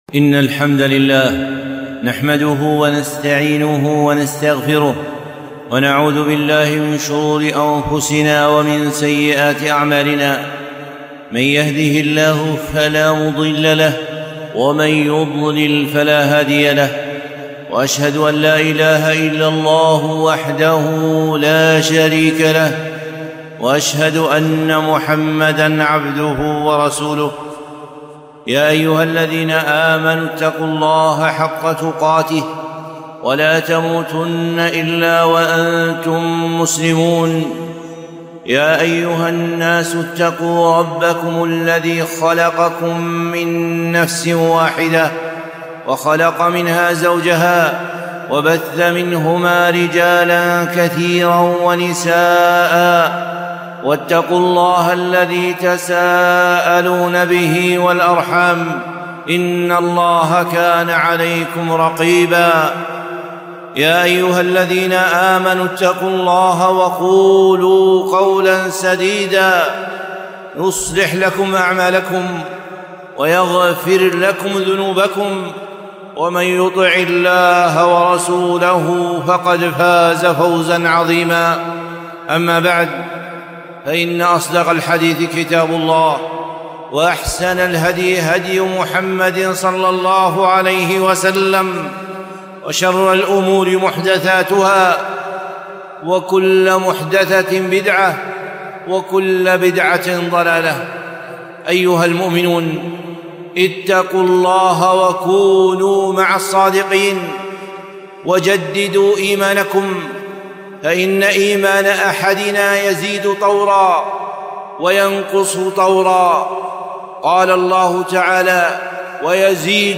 خطبة - زيادة الإيمان بالقرآن 5-1-1443